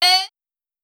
Metro Vox 6.wav